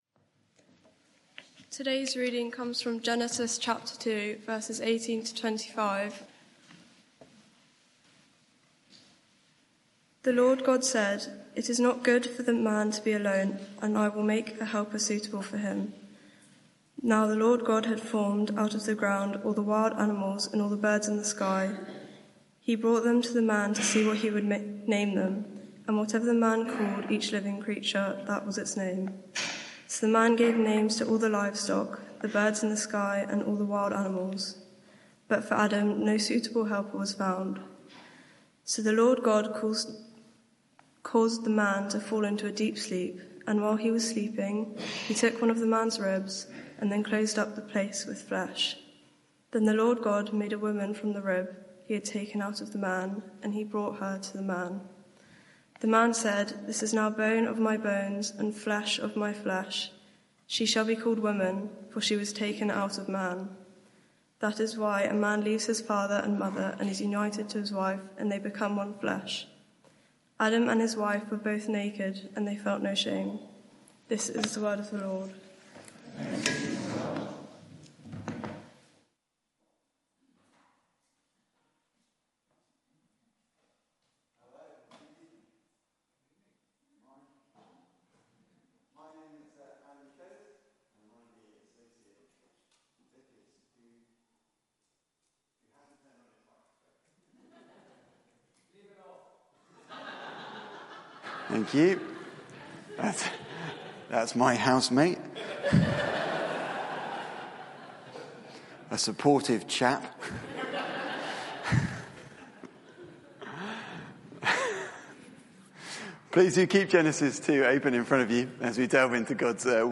Media for 6:30pm Service on Sun 27th Oct 2024 18:30 Speaker
Passage: Genesis 2:18-25 Series: Life in God's World Theme: Sermon (audio)